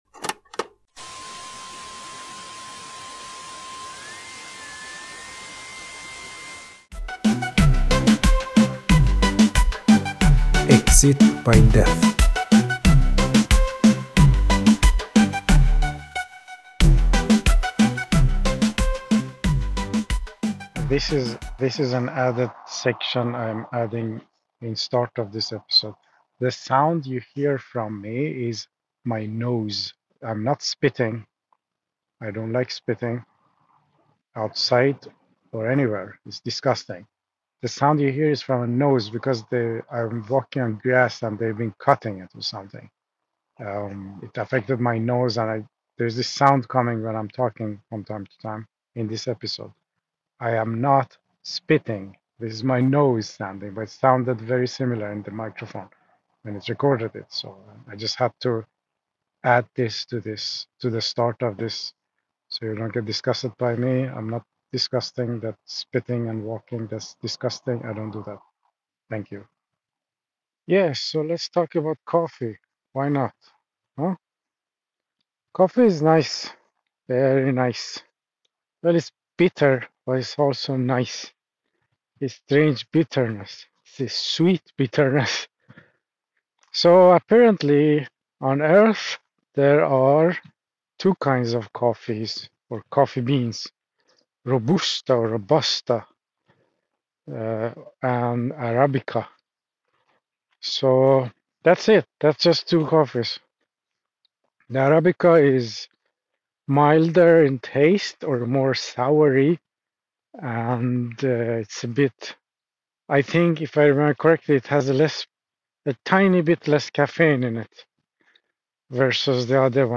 A quirky talk on coffee, comparing Arabica and Robusta beans, instant coffee benefits, and the importance of quality kitchen tools for brewing.
The sound you hear is from a nose because they I'm walking on grass and they've been cutting it or something.